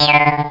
Jaw Harp Sound Effect
Download a high-quality jaw harp sound effect.
jaw-harp.mp3